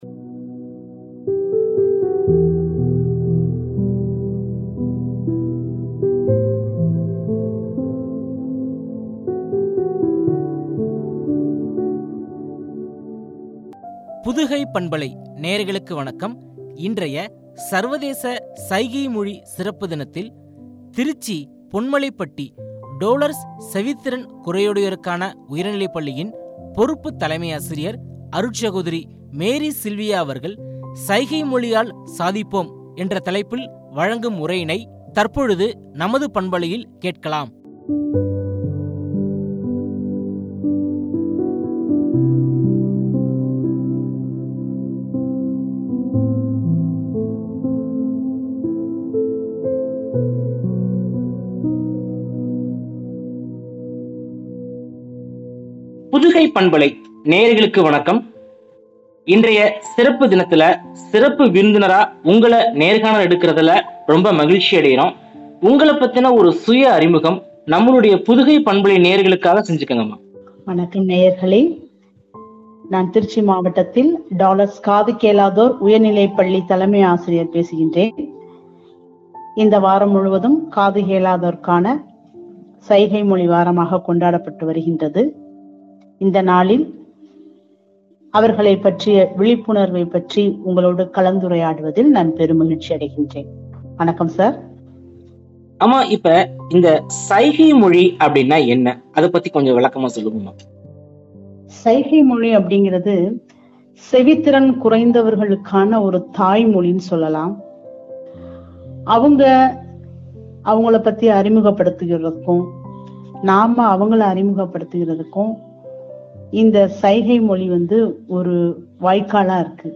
“சைகை மொழியால் சாதிப்போம்” எனும் தலைப்பில் வழங்கிய உரையாடல்.